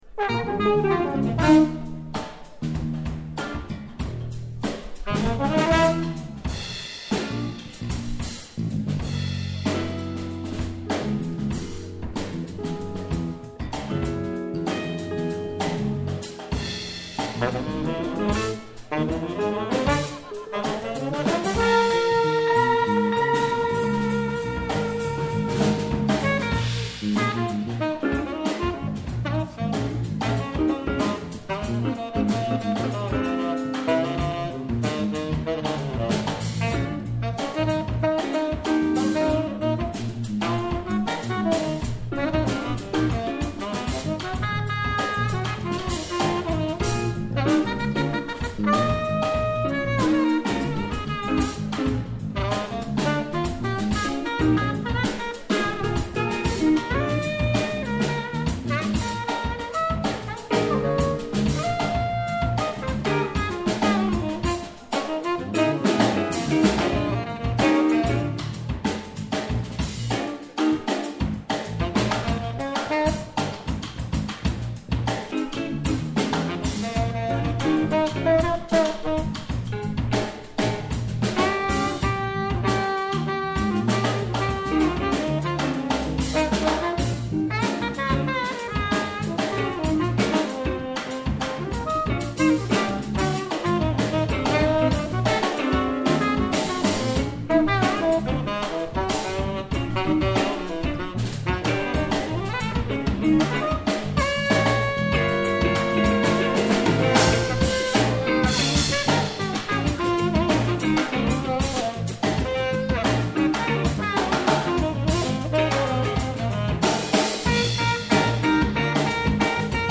live in der Sandgrube 25 in Speikern am 16.7.2022
saxophone
trombone
guitar
bass
drums